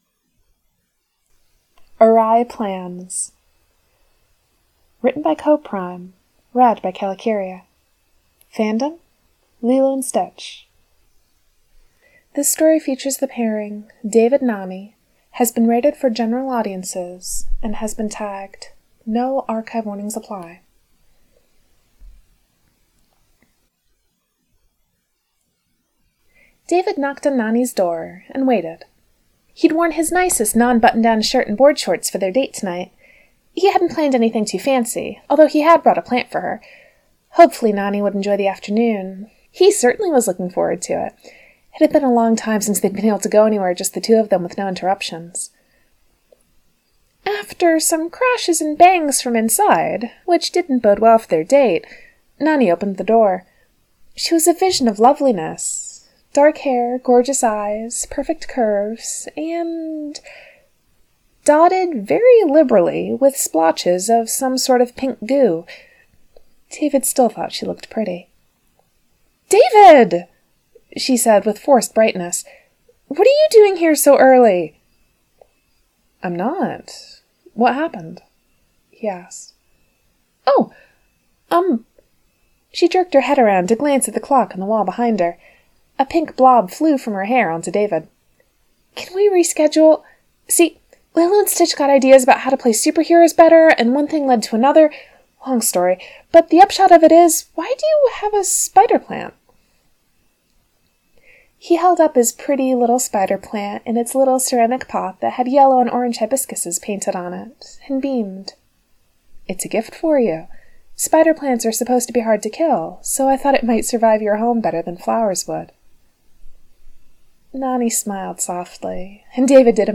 Lilo & Stitch, Moana podfics for the Chromatic Characters Podfic Anthology